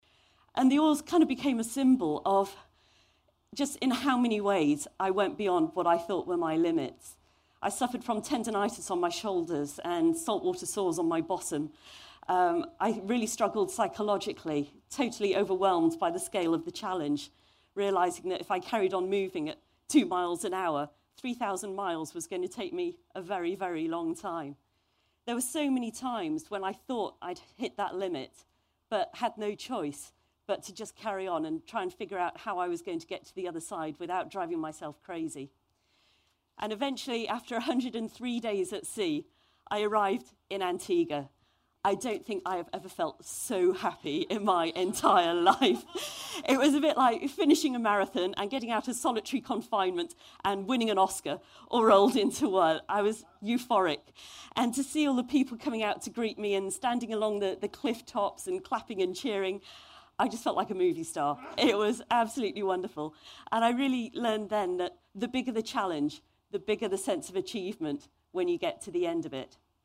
TED演讲：我为什么划船横渡太平洋(4) 听力文件下载—在线英语听力室